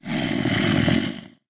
sounds / mob / zombie / say1.ogg